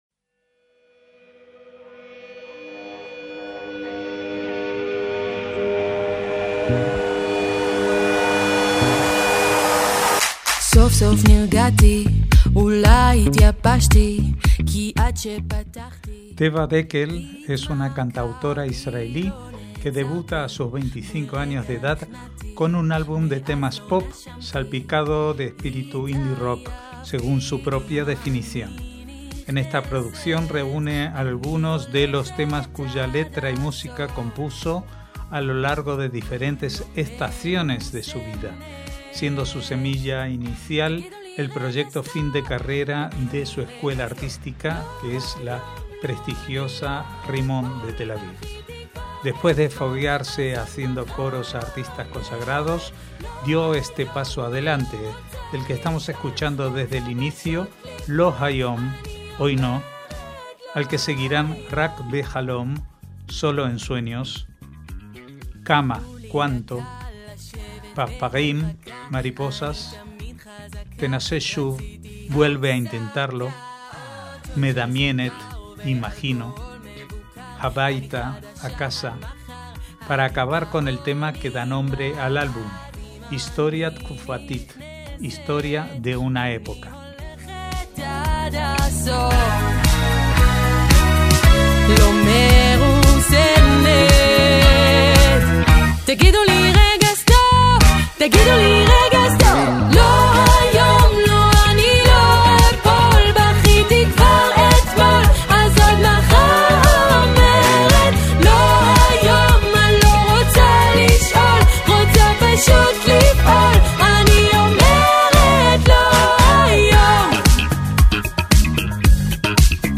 MÚSICA ISRAELÍ
un álbum de temas pop, salpicado de espíritu indie-rock